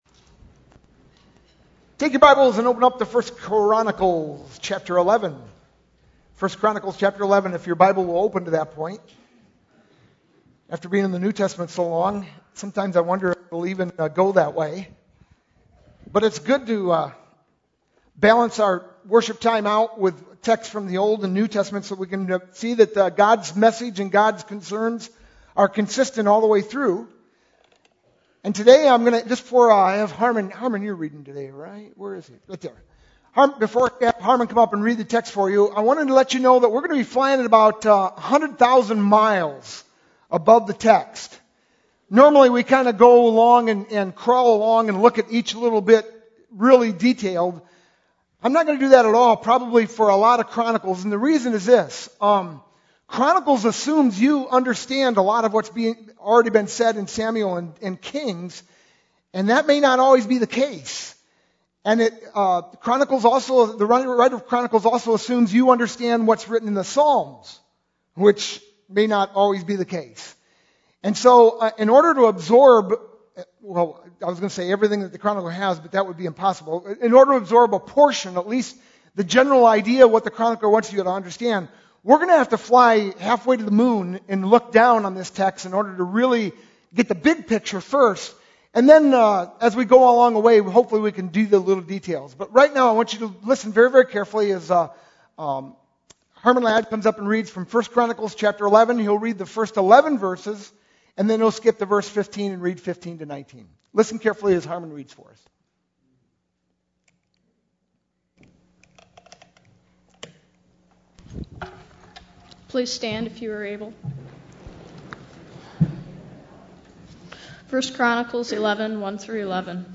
Sunday, April 29th, 2012 (Youth Sunday) I Chronicles 11 Sermon Title: “Worship Leader”
sermon-4-29-12.mp3